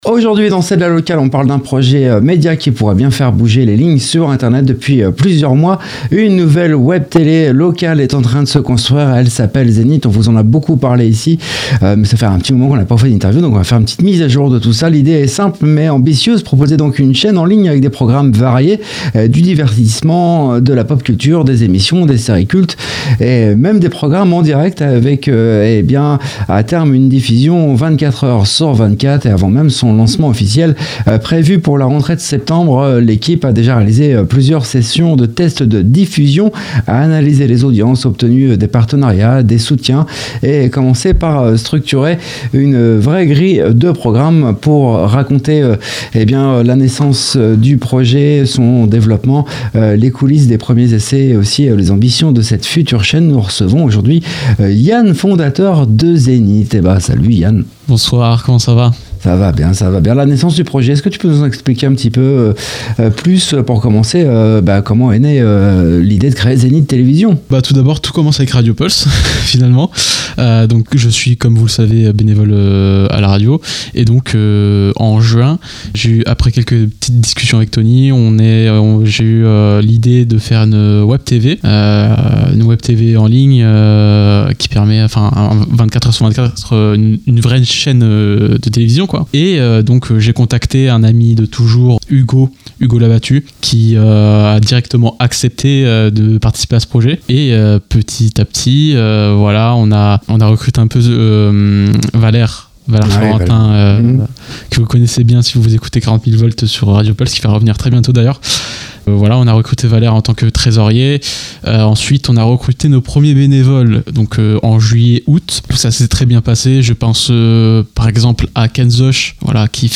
Une conversation passionnante sur la création d’un nouveau média et sur les défis que représente le lancement d’une web-télévision indépendante. culture local webtv